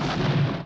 BSG FX - Explosion 02 Download Picture
BSG_FX-Explosion_02.wav